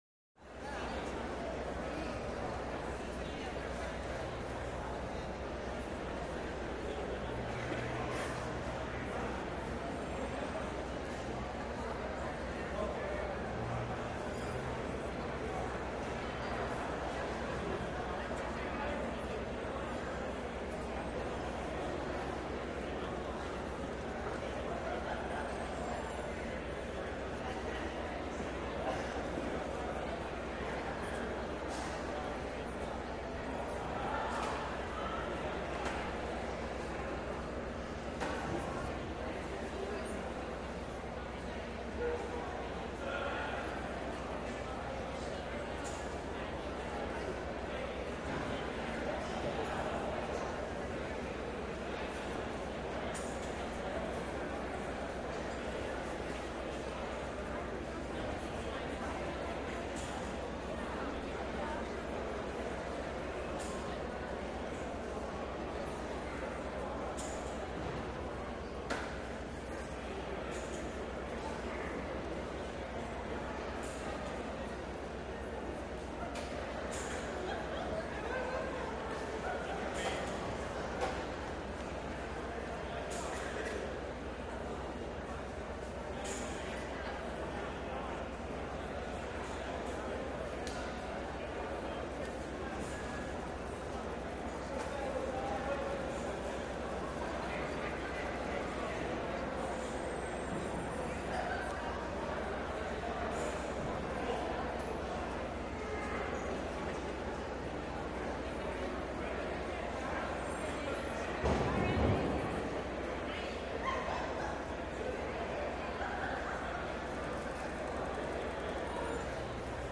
Train Station - Busy Station